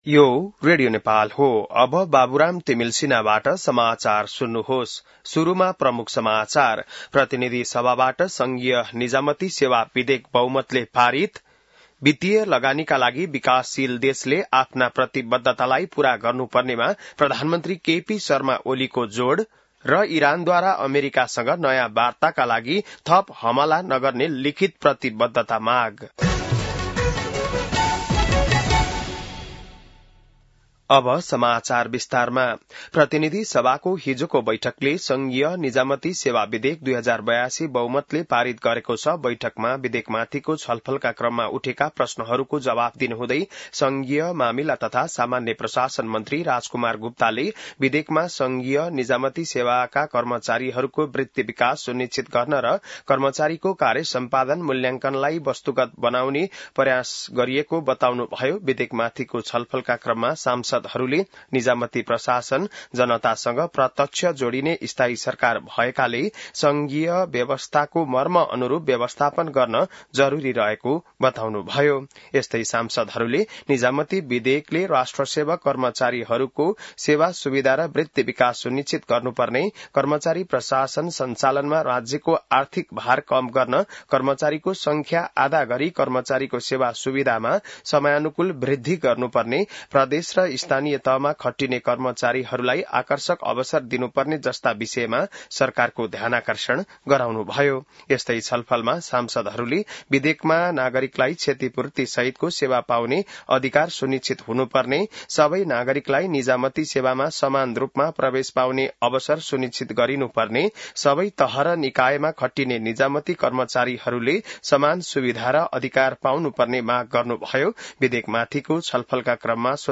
बिहान ९ बजेको नेपाली समाचार : १६ असार , २०८२